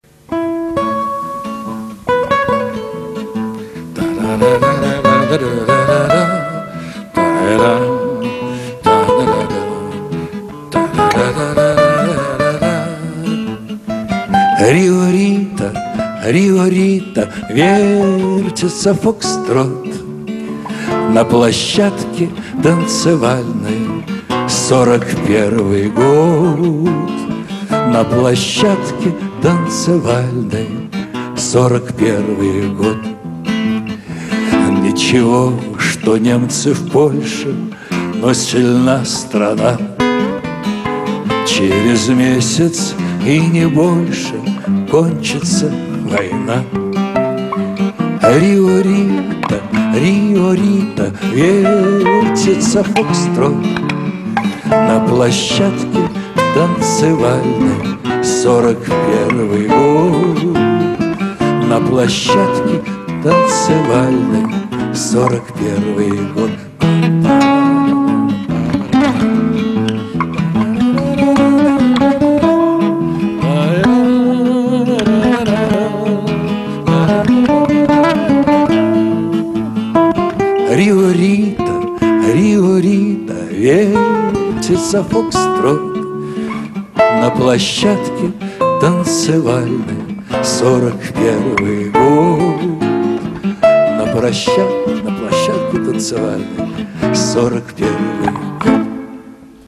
Недавно в клубе Эльдар состоялся концерт музыки Петра Тодоровского.
Первые 3 записи - с этого концерта.